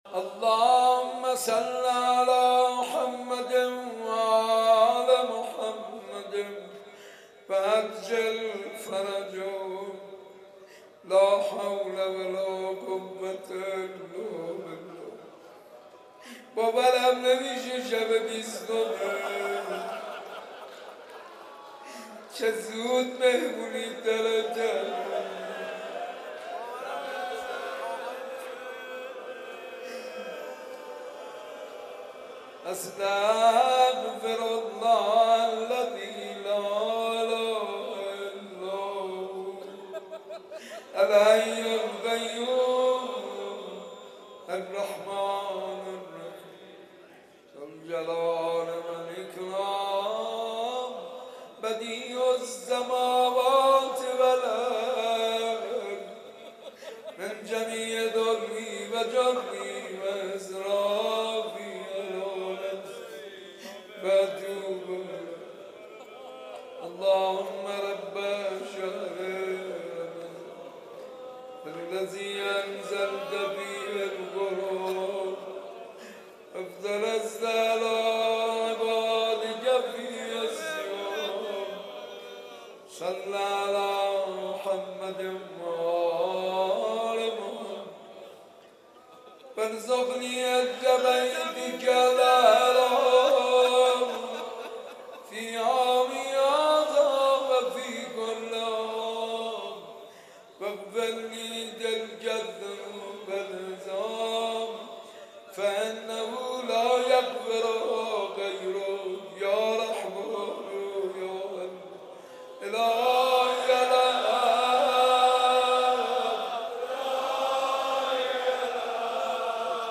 قرائت جزء بیستم، مناجات و دعای روز بیستم ماه مبارک رمضان - تسنیم
ترتیل جزء بیست م قرآن کریم با صدای استاد پرهیزکار